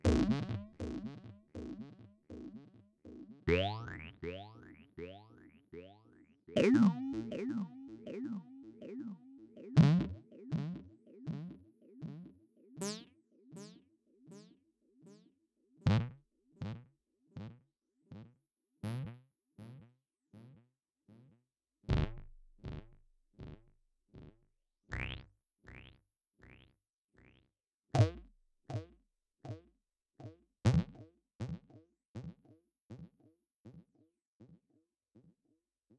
Cartoon_boing
Category 🤣 Funny
boing bounce cartoon silly sound effect free sound royalty free Funny